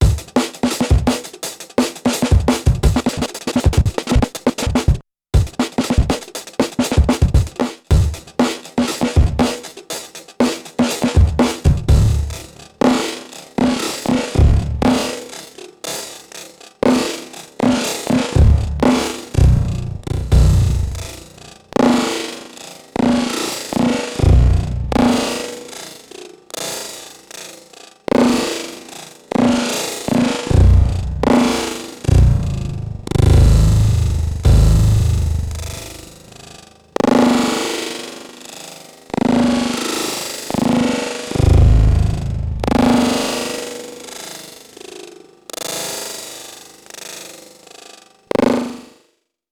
Here’s a break, first played in one shot mode, then stepped 1-8bars at 120BPM, then 76BPM, then 30BPM